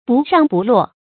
不上不落 注音： ㄅㄨˋ ㄕㄤˋ ㄅㄨˋ ㄌㄚˋ 讀音讀法： 意思解釋： 形容事情無著落，處境為難。